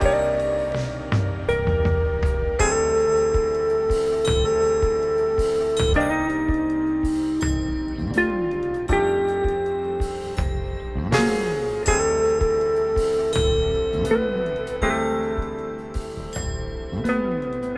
chopped jazz sample_81bpm.wav